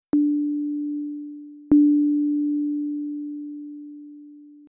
Aha, ich höre oben rum etwas. Das ist also Aliasing?